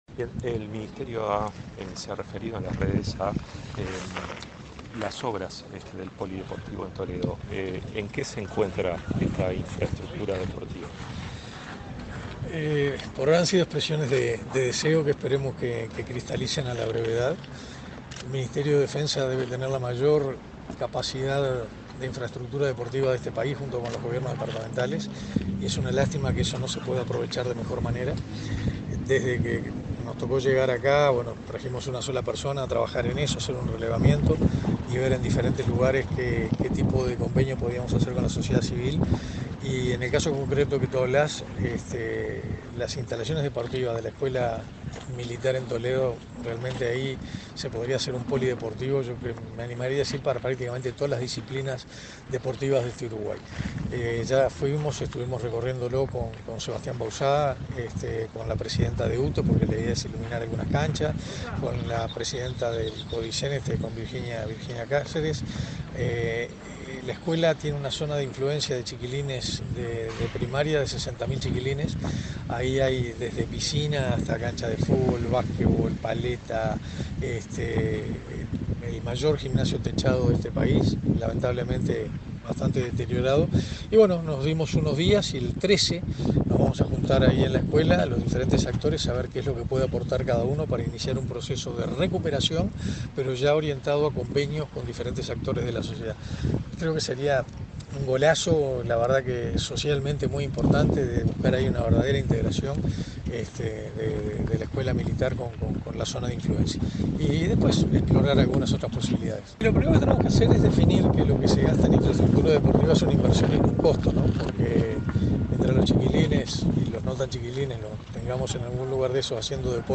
Entrevista al ministro de Defensa Nacional, Armando Castaingdebat